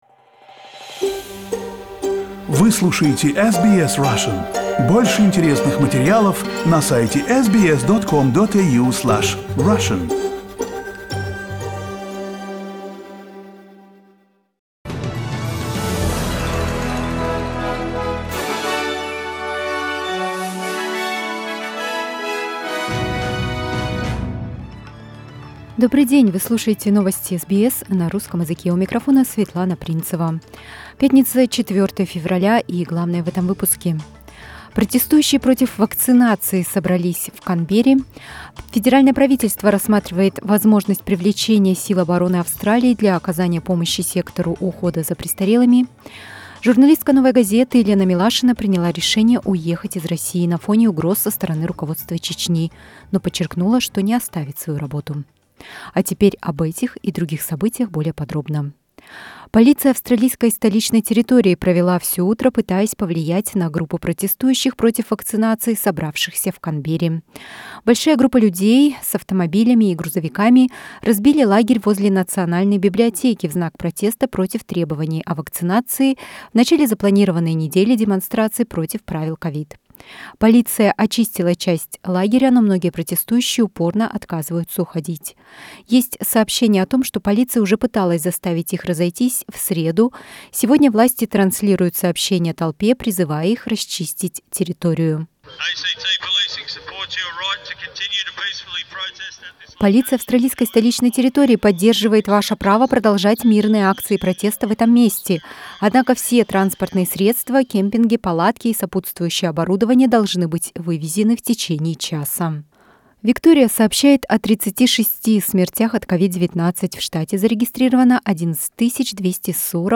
Listen to the top news headlines from Australia and the world on SBS Russian.